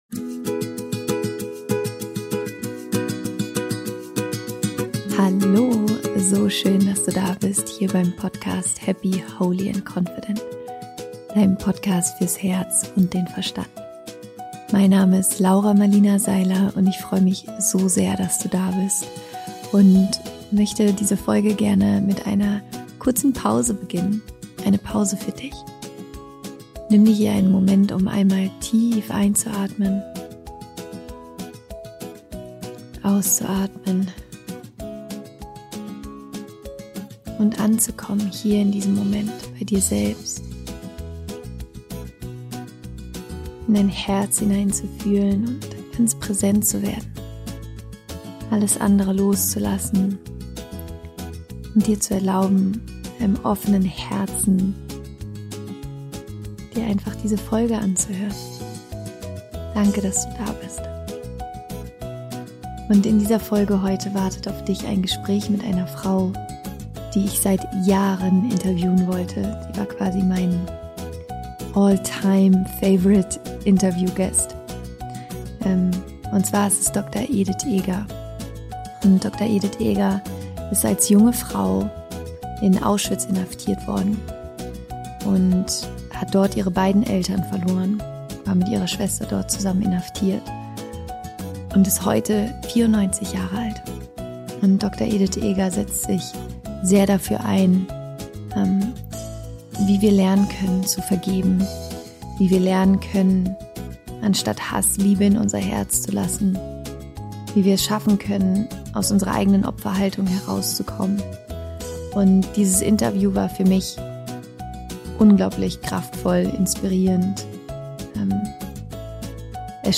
Dr. Edith Eger ist heute 94 Jahre alt und teilt seit vielen Jahren ihre Geschichte vom Überleben des Holocaust und ihrer Gefangenschaft in Auschwitz.
Im Interview sprechen wir darüber, wie wir lernen können, sogar die schlimmsten Erfahrungen zu vergeben und wie wir Liebe anstatt Hass wählen.